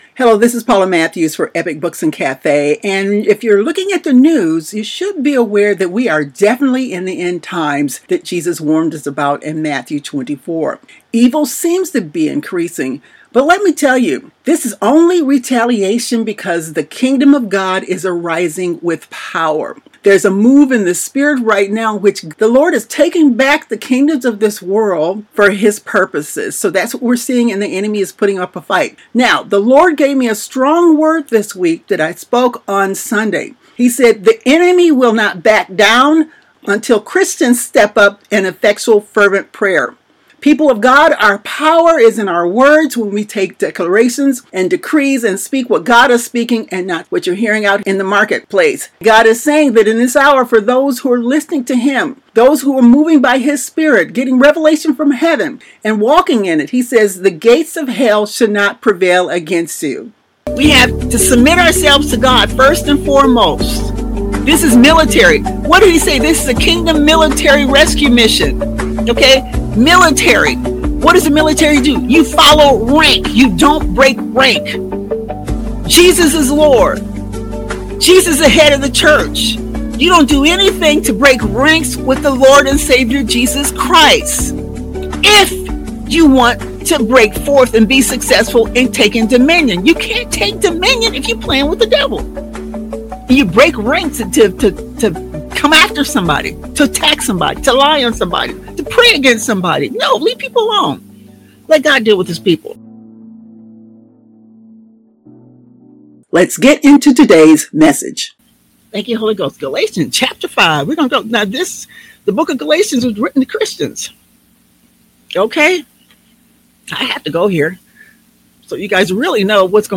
[We apologize for any audio distortion.]